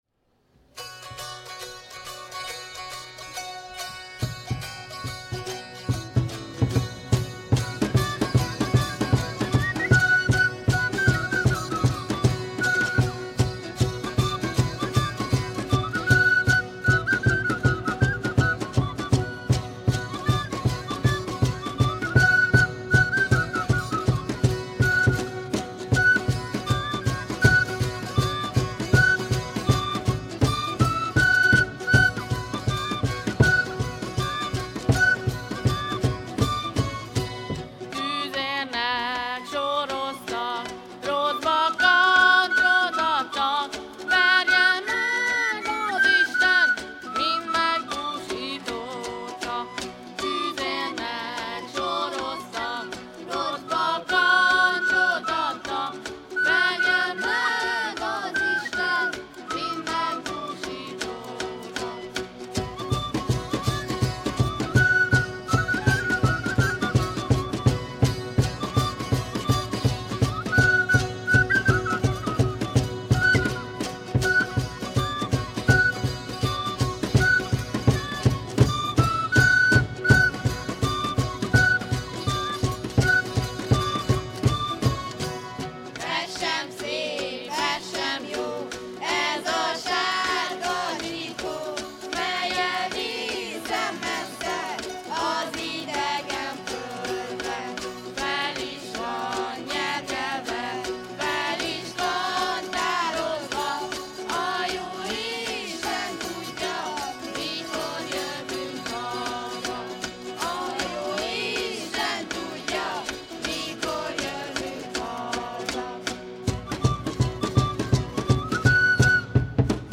A Jégcsarnokban rögzített hanganyag
14 Cinege zenekar (Felsőzsolca) – Moldvai népdalfeldolgozás
14-Cinege-zenekar-Moldvai-népdalfeldolgozás.mp3